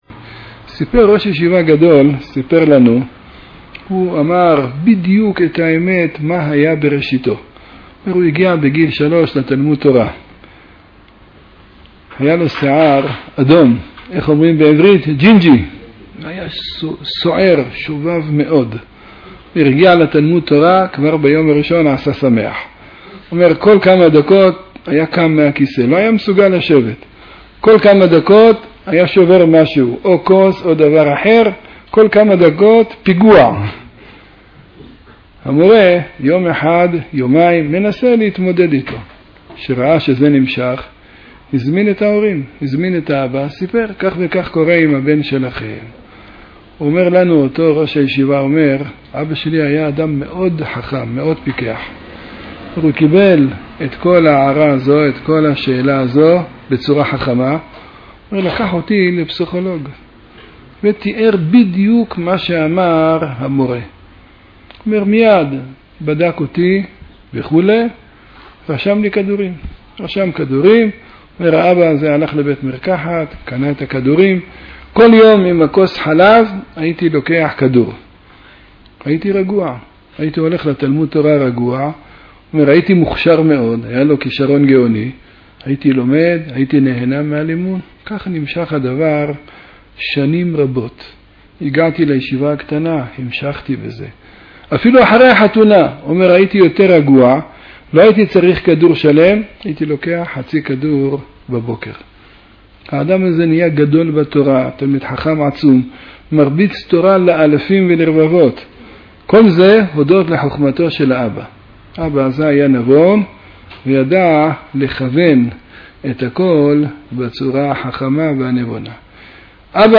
תוכלו לשמוע את הגאון הר' יעקב יוסף זצ"ל בעניין. קבצים מצורפים היפראקטיביות.mp3 1.2 MB · צפיות: 35